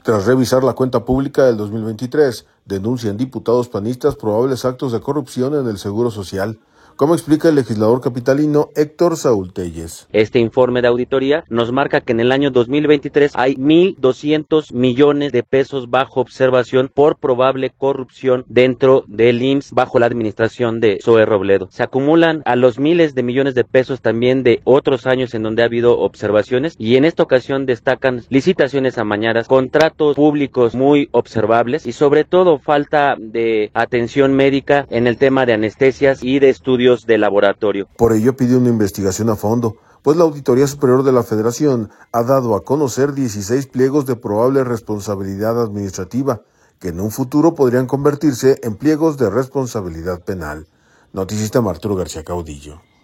Tras revisar la cuenta pública del 2023, denuncian diputados panistas probables actos de corrupción en el Seguro Social, como explica el legislador capitalino Héctor Saúl Téllez.